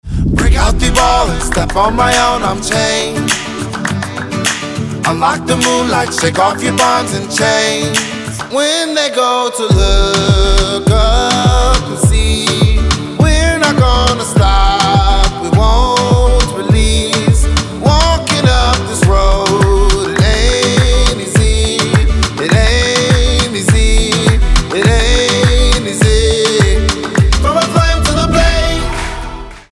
• Качество: 320, Stereo
мужской вокал
зажигательные
dance
Electronic
club
приятные
tropical house